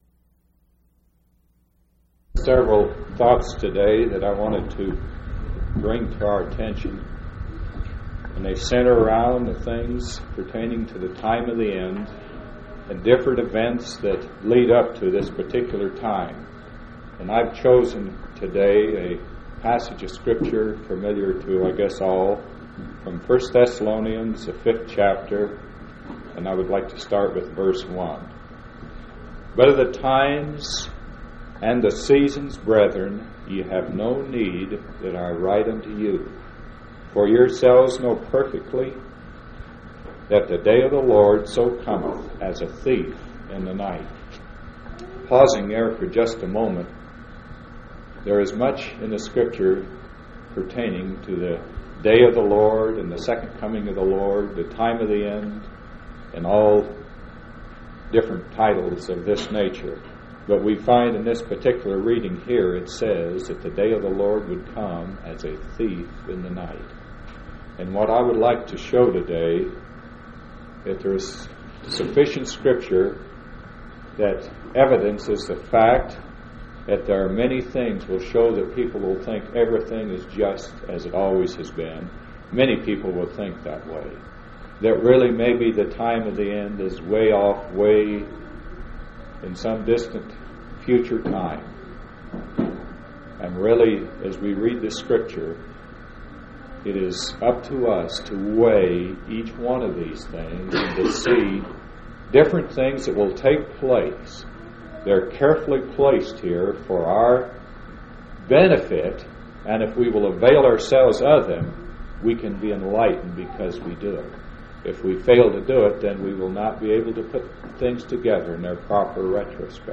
10/15/1978 Location: Grand Junction Local Event